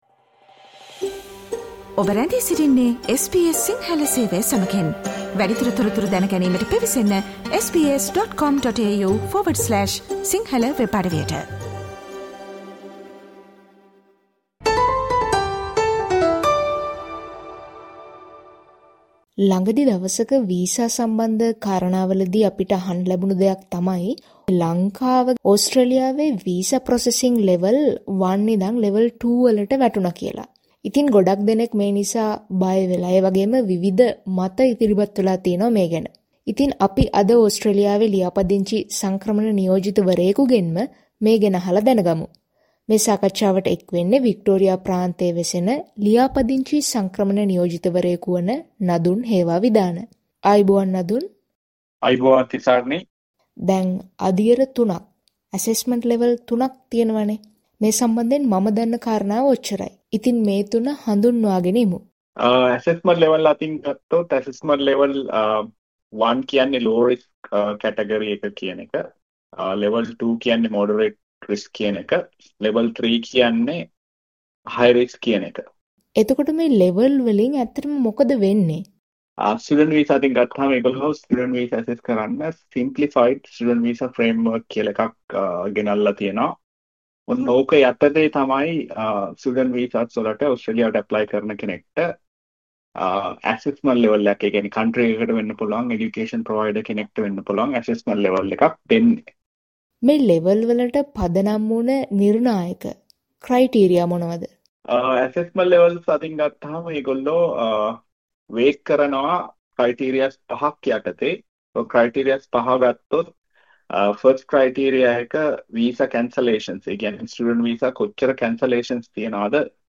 SBS සිංහල සේවය සමඟ පැවැත් වූ සාකච්ඡාව.